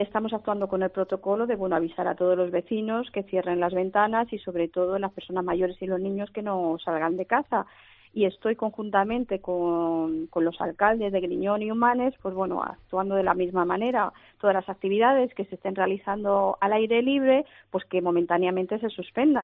María del Valle Luna, alcaldesa de Moraleja, en COPE